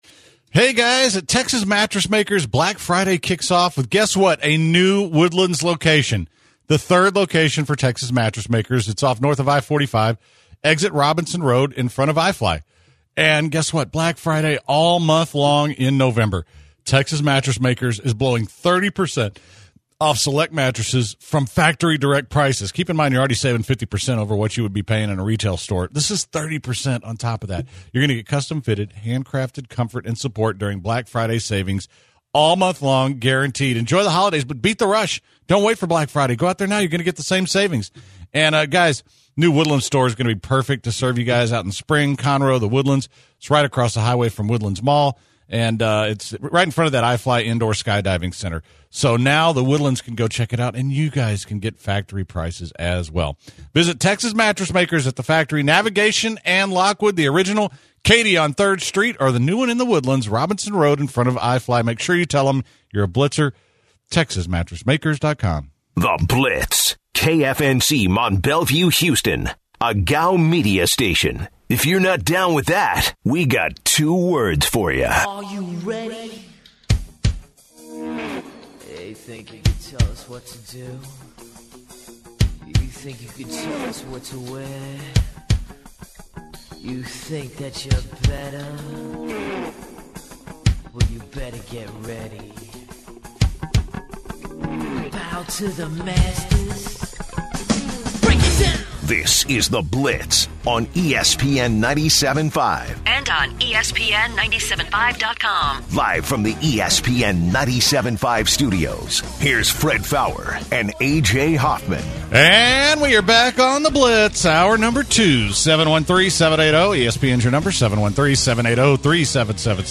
Headliner Embed Embed code See more options Share Facebook X Subscribe The guys start the second hour of the show debating if this Texans team is different than years past before looking at the new College Football Playoff rankings. Next, they revisit the Astros cheating scandal and take some calls.